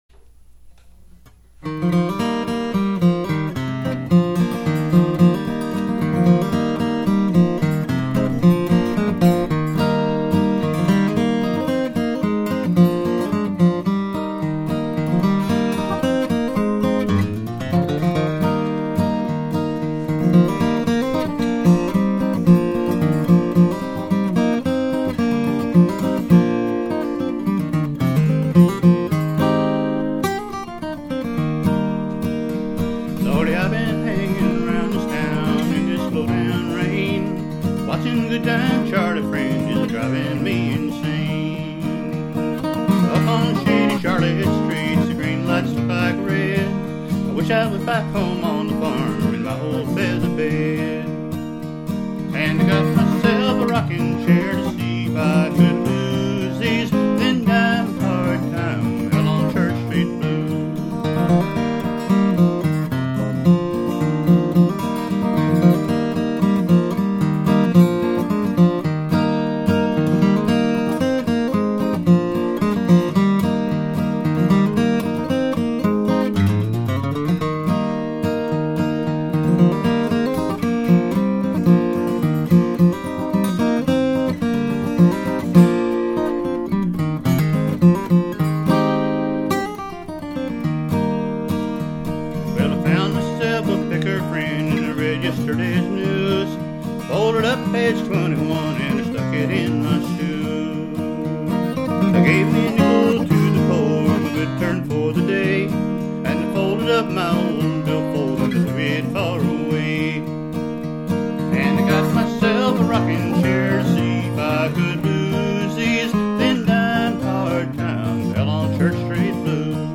Быстрый пробежка, один микрофон, гитара / вокал вместе